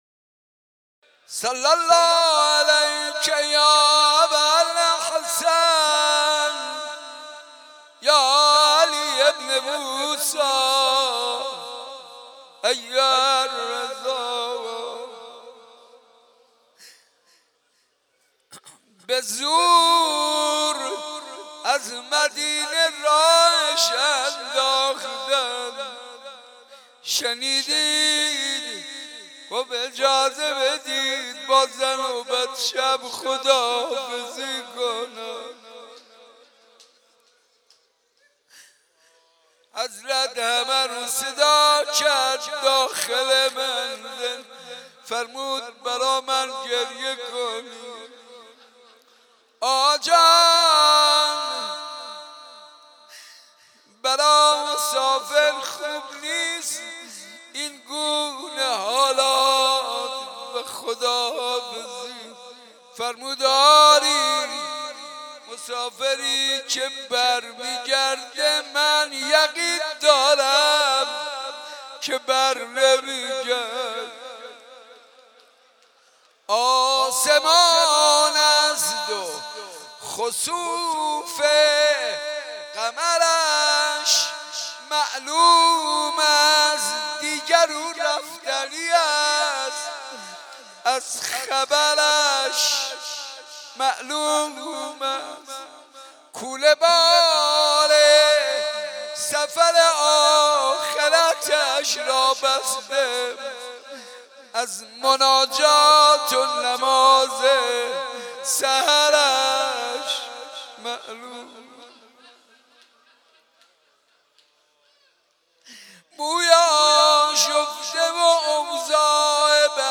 حسینیه صنف لباس فروشان
روضه حضرت علی بن موسی الرضا (علیه السلام)